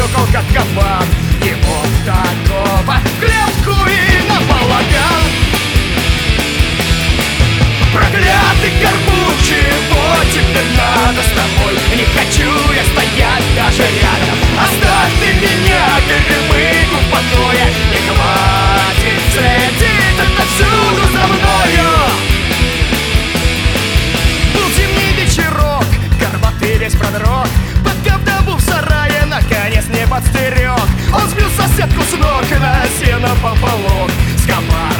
Жанр: Рок / Русские